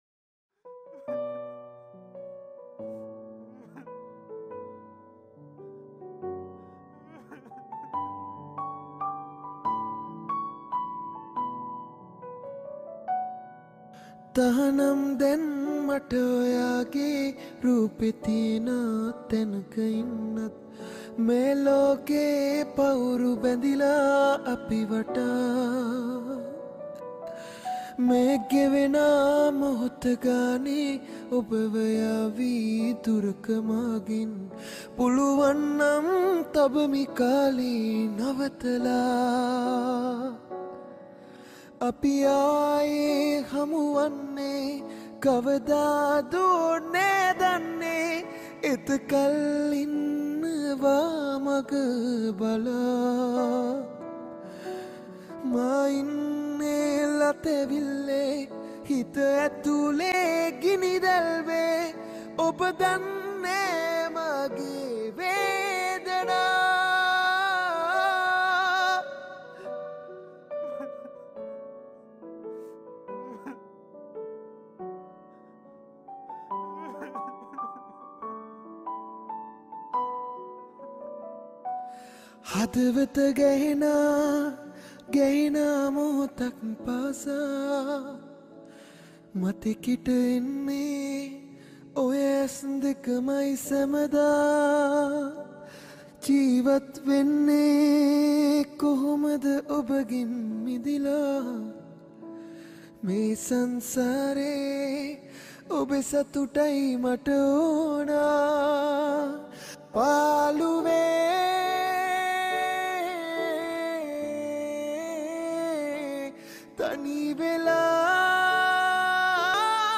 High quality Sri Lankan remix MP3 (5.6).